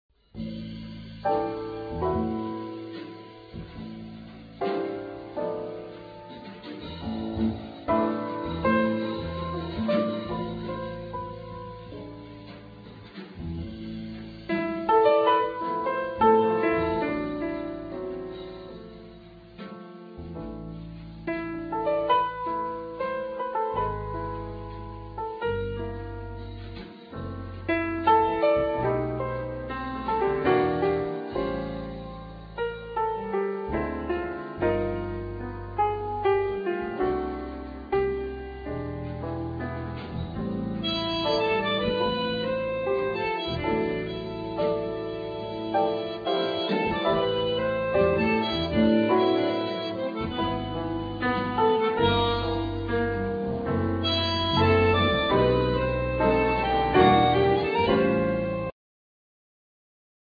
Piano
Double bass
Drums
Ac guitar
Bandoneon